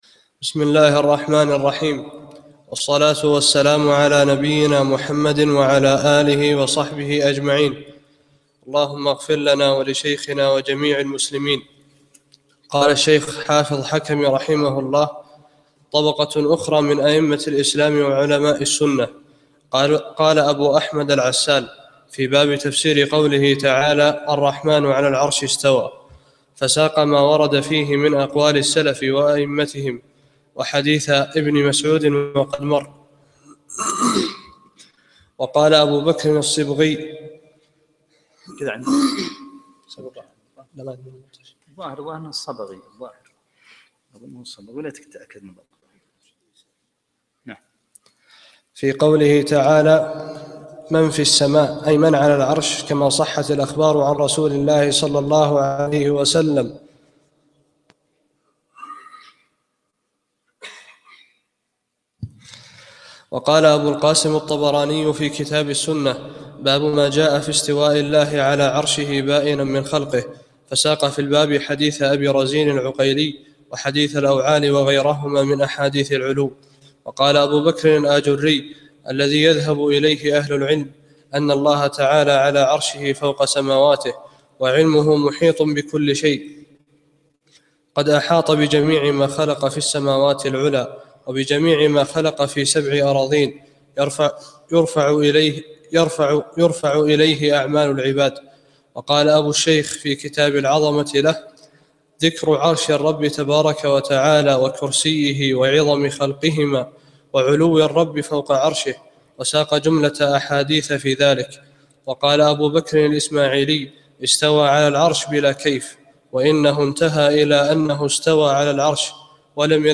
23- الدرس الثالث والعشرون